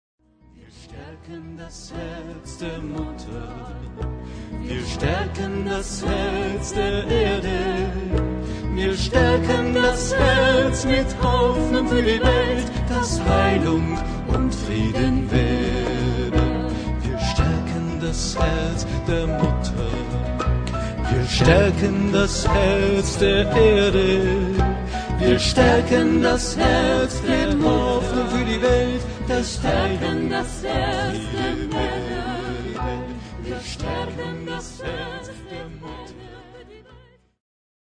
Ihr ausgezeichneter und vielseitiger Pianist